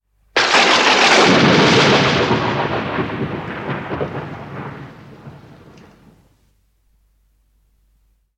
Bbc Thunder Sound - Botón de Efecto Sonoro